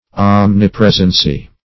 Omnipresency \Om`ni*pres"en*cy\, n.